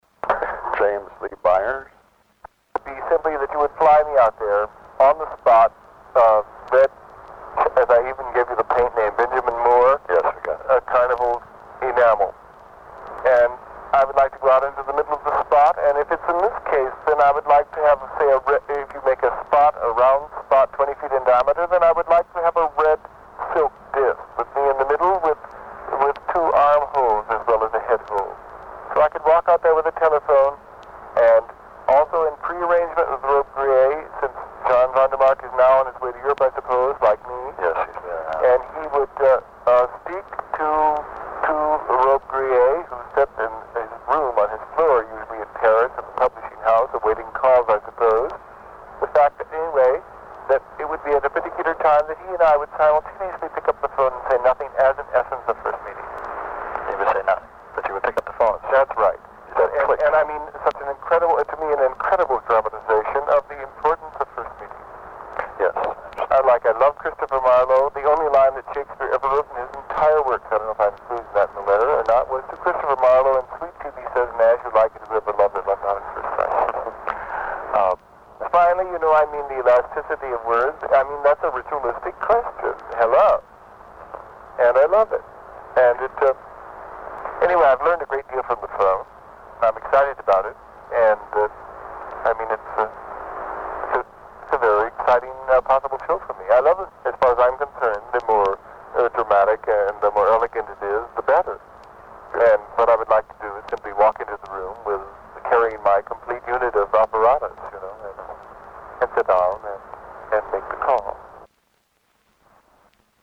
audio extrait du vinyle de 1969